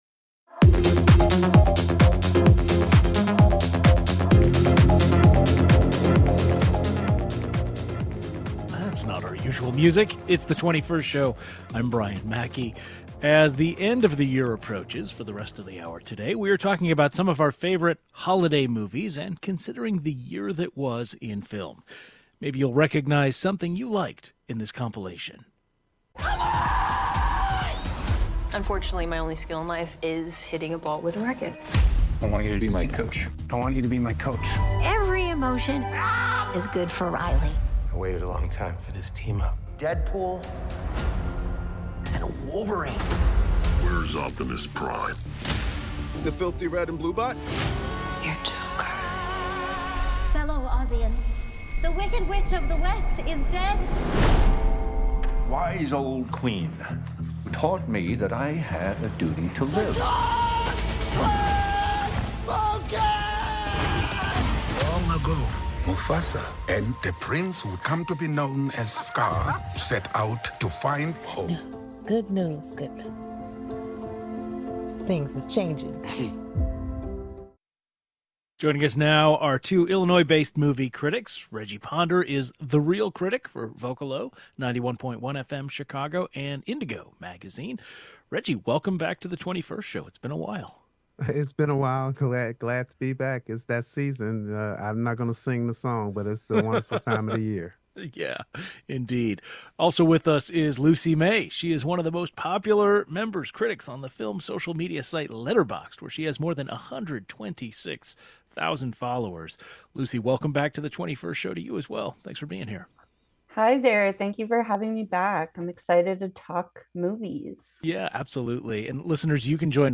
It's been an interesting year for movies. Two movie critics join today's program as we take a look back at the biggest movies of 2024, and also explore favorite holiday movies of the season.